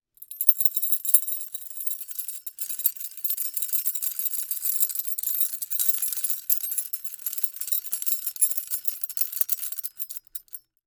Here is a recording of jangling keys, made with Rode NT1A microphones at 96 kHz, 24 bit. It has extreme high frequencies at high levels, and is useful for testing high frequency sensitivity.
Key Jangling
keyJangle2-nt1a.flac